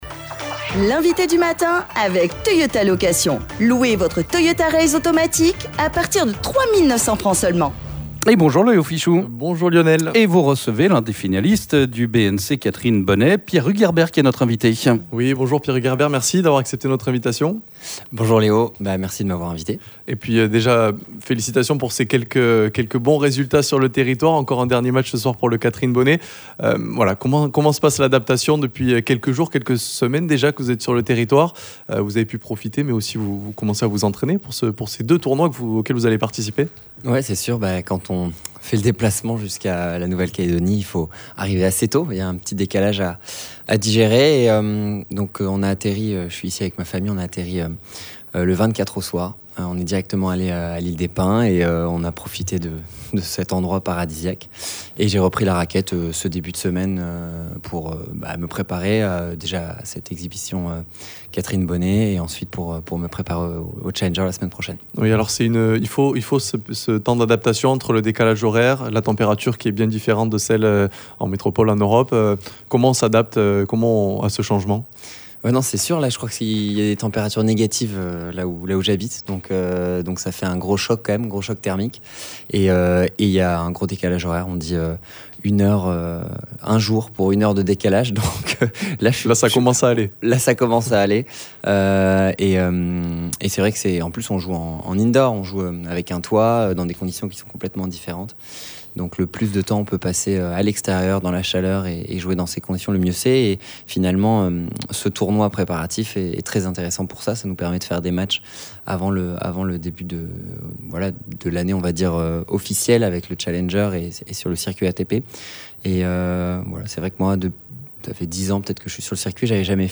Nous parlons tennis avec notre Invité du Matin…le 155ème à l’ATP et ancien n°2 mondial en double, Pierre-Hugues Herbert, est dans nos studios. Nous évoquons sa participation au BNC Catherine Bonnet et au BNC Tennis Open la semaine prochaine mais aussi ses objectifs pour cette nouvelle saison…Pierre-Hugues Herbert qui a déjà remporté tous les titres du Grand Chelem et les Masters en double.